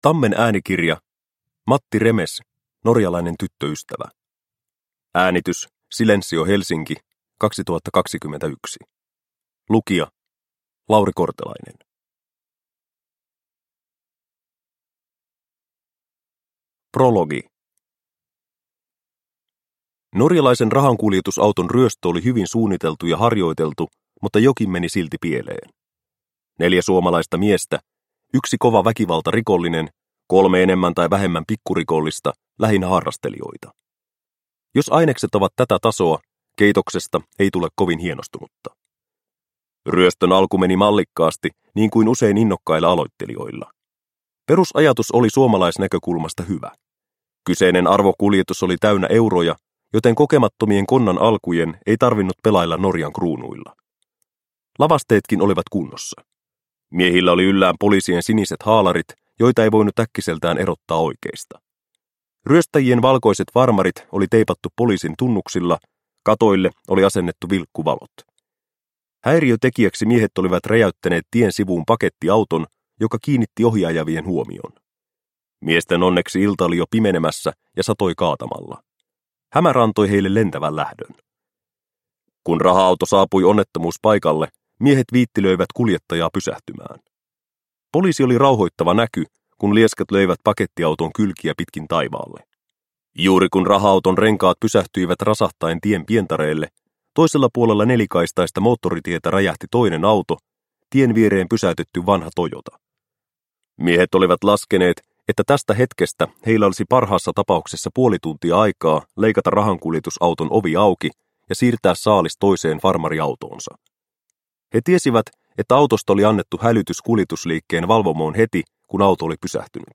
Norjalainen tyttöystävä – Ljudbok – Laddas ner